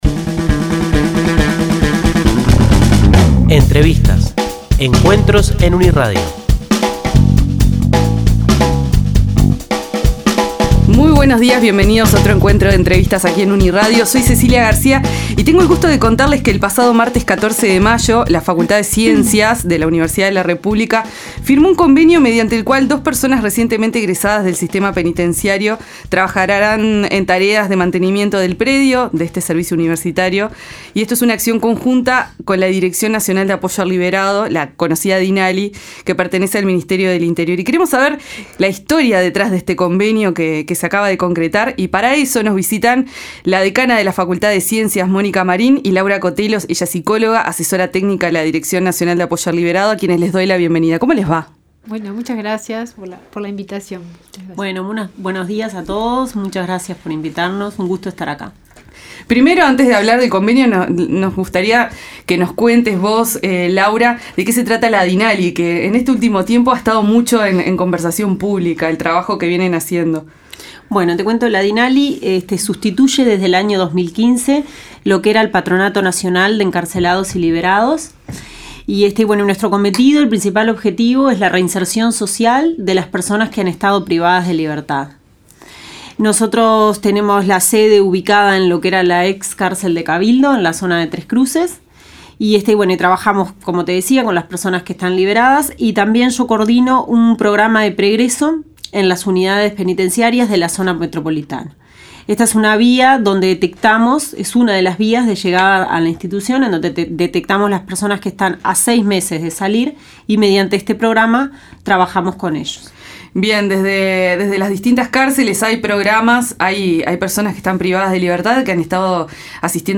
Entrevistas: FCien y Dinali firmaron convenio de cooperación